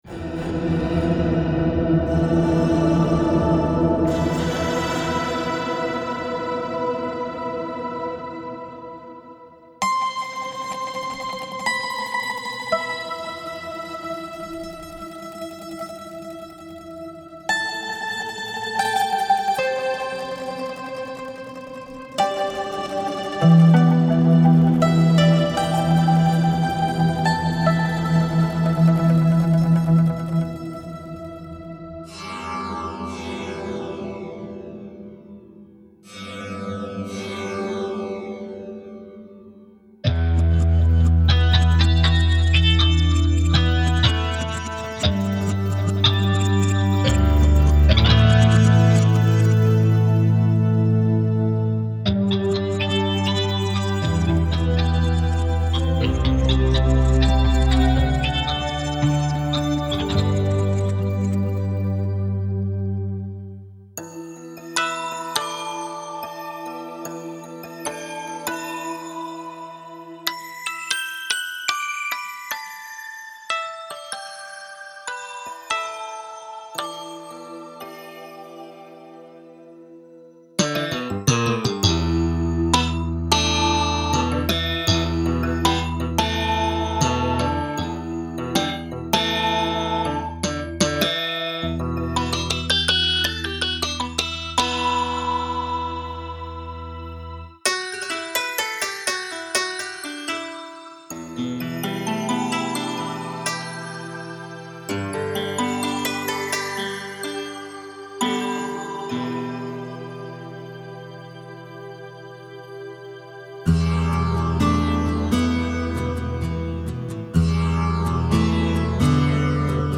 SynGuitars1.mp3